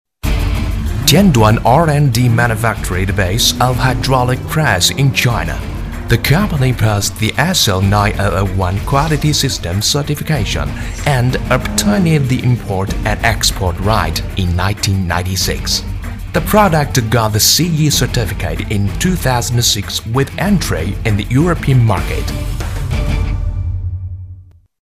标签： 磁性
配音风格： 讲述 磁性